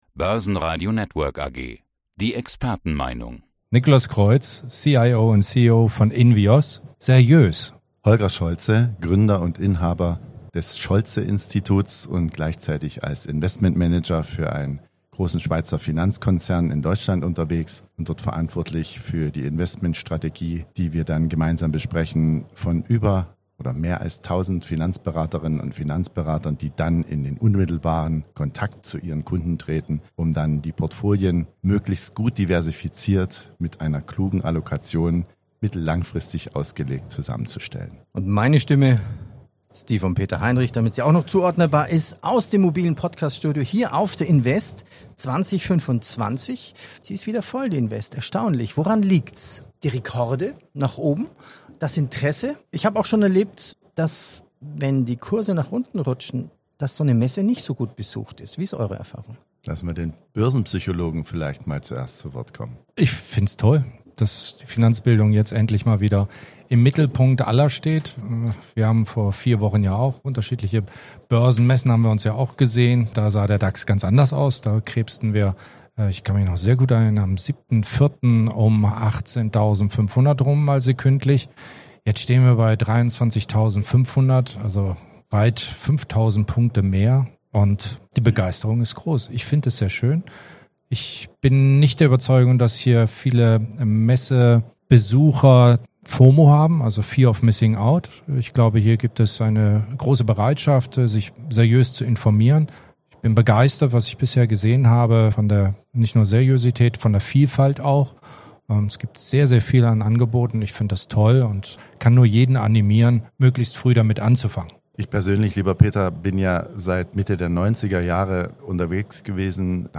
Zum Börsenradio-Interview